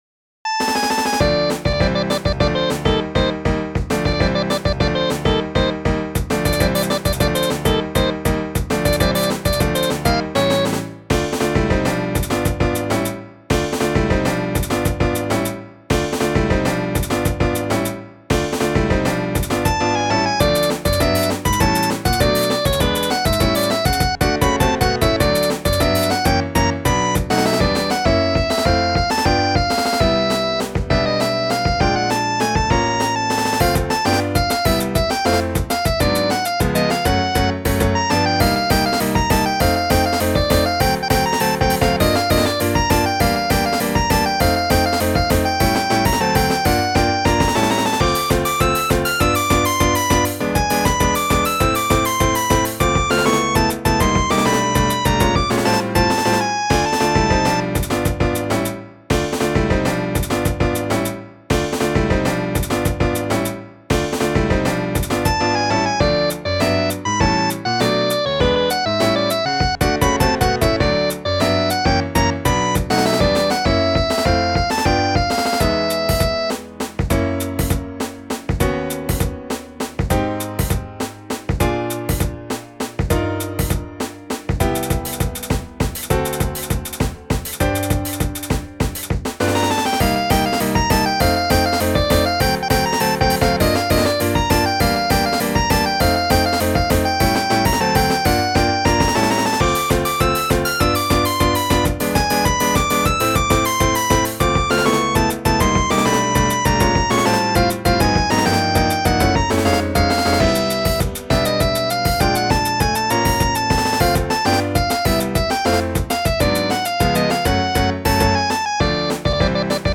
デモ版：
iOS版GarageBandで制作した音源。